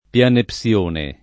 vai all'elenco alfabetico delle voci ingrandisci il carattere 100% rimpicciolisci il carattere stampa invia tramite posta elettronica codividi su Facebook pianepsione [ p L anep SL1 ne ] s. m. (stor.) — mese dell’antico calendario attico (= ottobre-novembre)